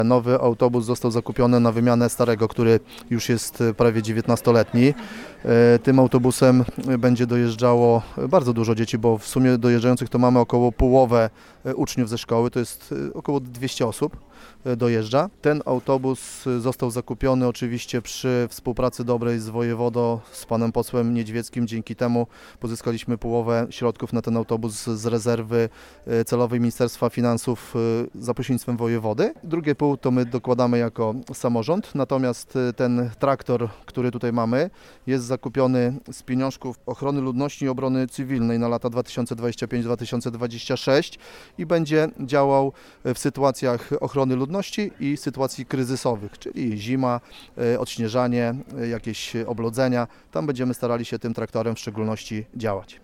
O szczegółach mówi Tomasz Rogowski, wójt gminy Filipów.